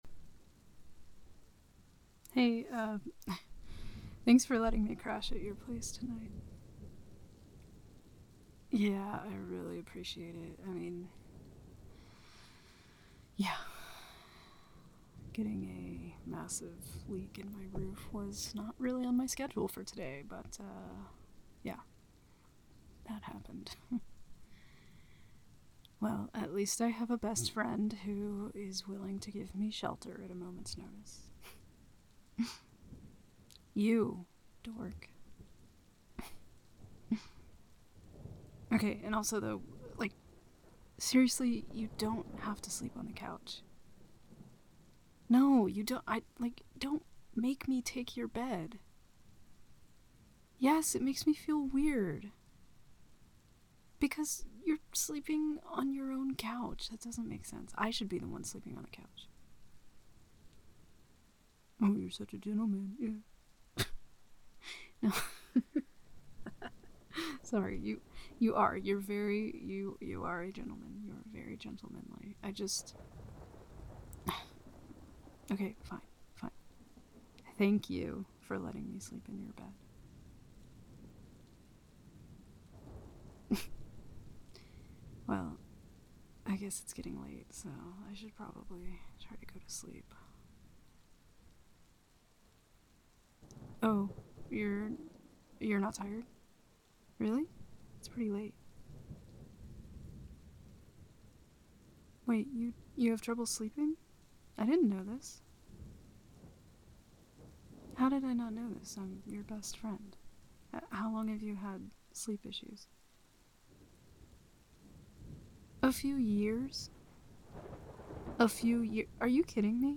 But when she finds out you have sleep issues, she INSISTS on cuddling you and whispering softly in your ear to help you fall asleep. This patreon version is a full 30-minutes with the first half being talking and the second half rain sounds and breathing.